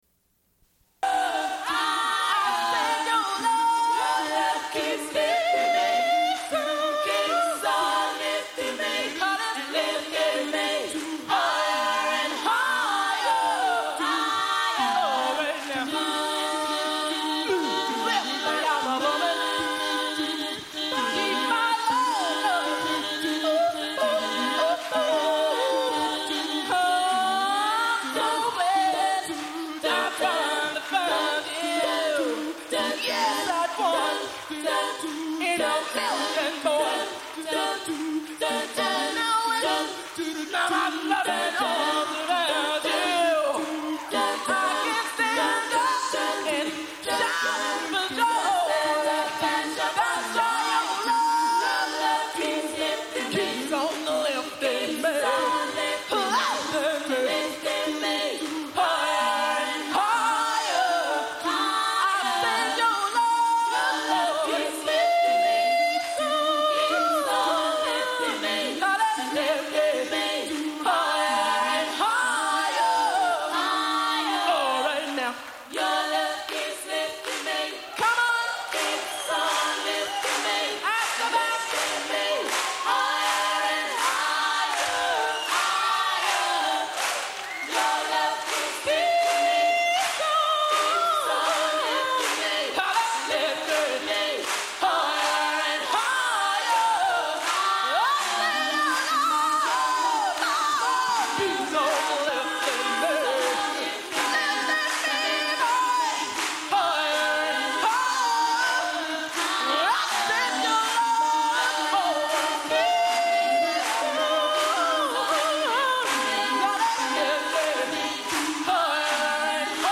Une cassette audio, face A00:31:44
Fin de l'émission en musique.